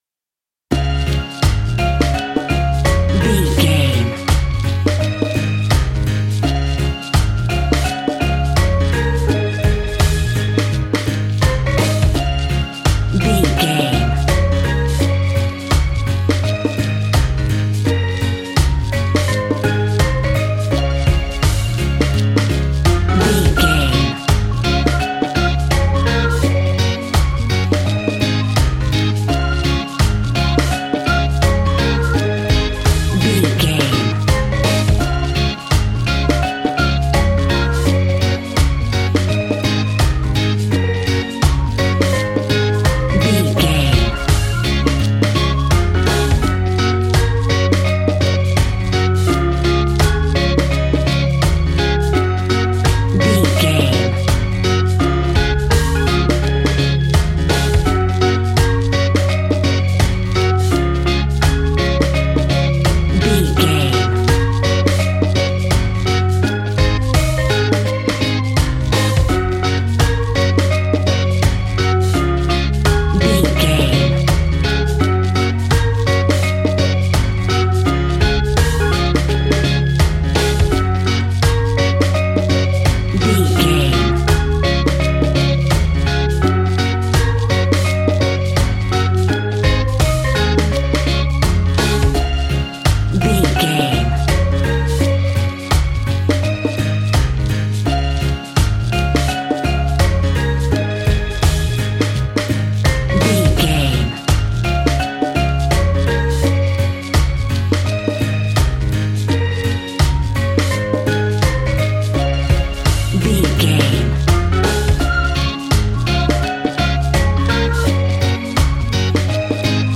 Uplifting
Ionian/Major
D♭
steelpan
drums
bass
brass
guitar